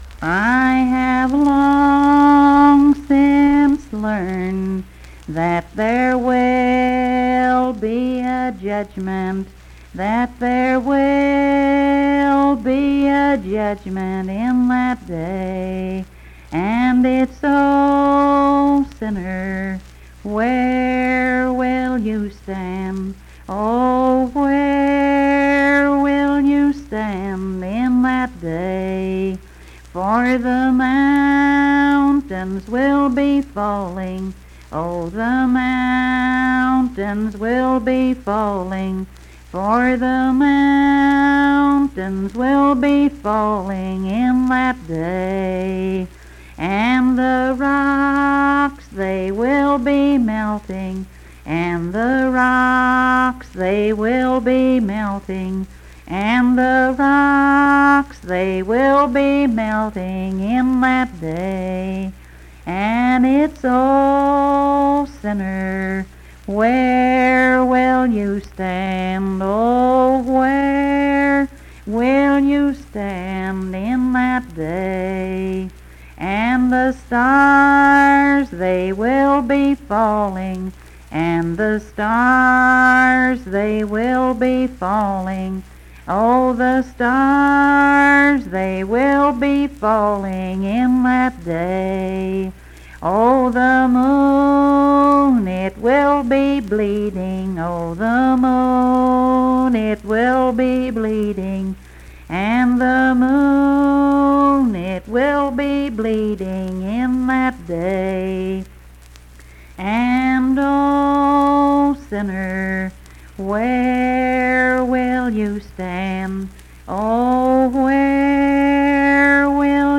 Unaccompanied vocal music performance
Verse-refrain 9(3).
Hymns and Spiritual Music
Voice (sung)